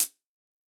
UHH_ElectroHatA_Hit-18.wav